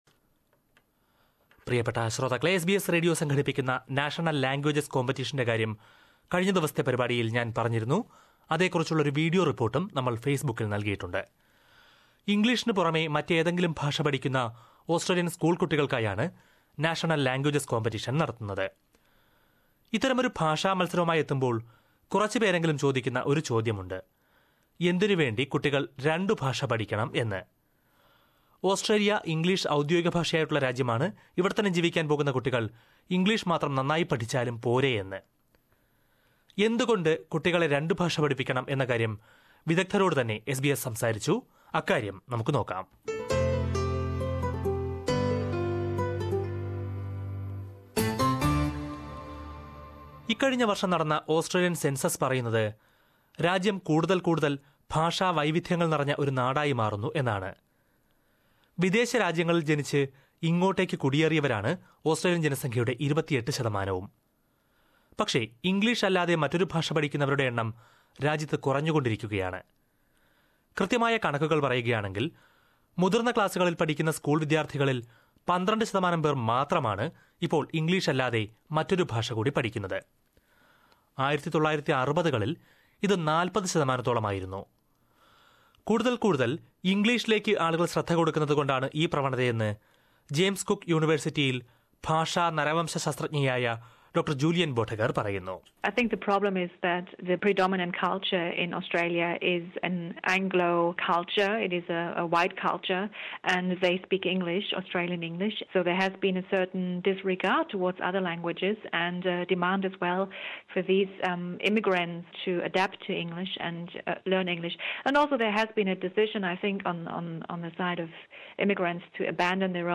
ഇംഗ്ലീഷ് ഔദ്യോഗിക ഭാഷയായ ഓസ്ട്രേലിയയിൽ കുട്ടികൾ എന്തിന് മറ്റൊരു ഭാഷ കൂടി പഠിക്കണം? ഇംഗ്ലീഷ് ഇതര ഭാഷ പഠിക്കുന്ന കുട്ടികൾക്കായി എസ് ബി എസ് നാഷണൽ ലാംഗ്വേജസ് കോംപറ്റീഷൻ സംഘടിപ്പിക്കുന്പോൾ നിരവധി പേർ ഉന്നയിക്കുന്ന ഒരു ചോദ്യമാണ് ഇത്. ഒന്നിലേറെ ഭാഷ പഠിക്കുന്നതുകൊണ്ട് കുട്ടികൾക്ക് എന്തു ഗുണമുണ്ട് എന്നതിനെക്കുറിച്ച് ഒരു റിപ്പോർട്ട് കേൾക്കാം.